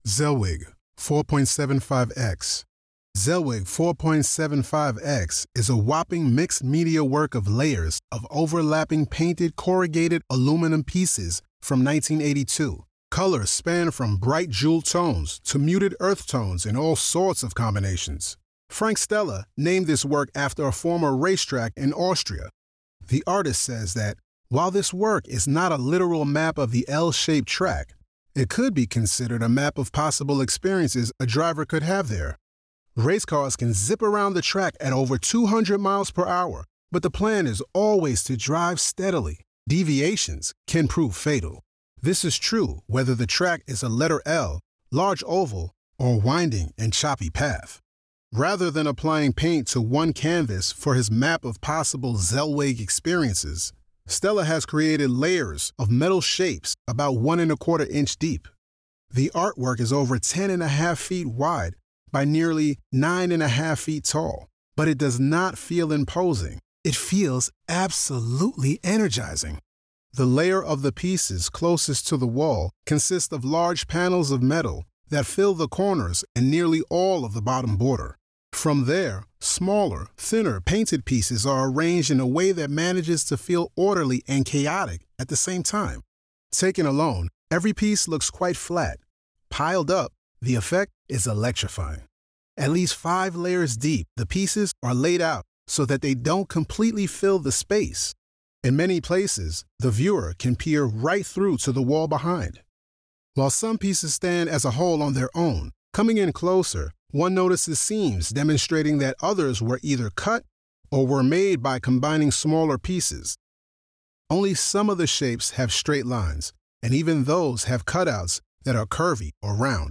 Audio Description (03:21)